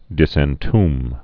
(dĭsĕn-tm)